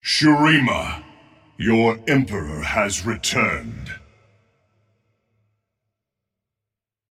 AZIR CHAMPION SELECT VOICE:
Azir_Select.ogg